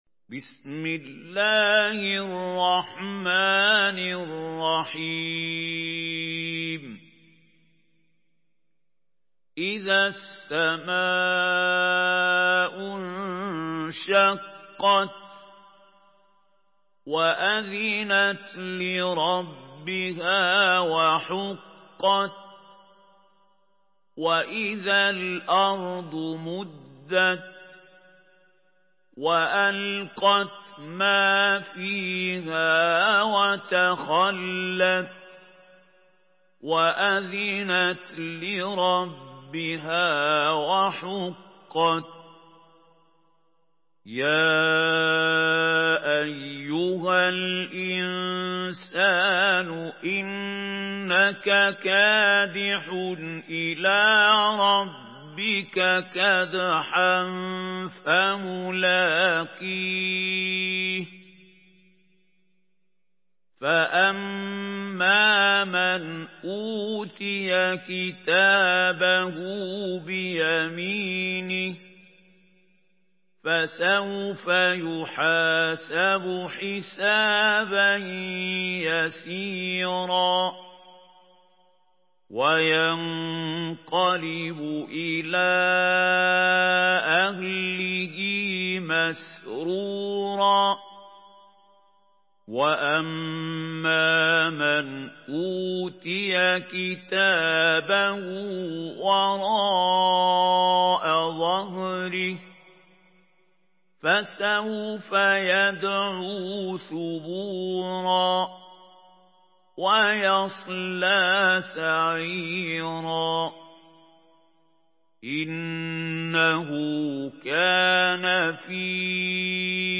Surah الانشقاق MP3 in the Voice of محمود خليل الحصري in حفص Narration
Listen and download the full recitation in MP3 format via direct and fast links in multiple qualities to your mobile phone.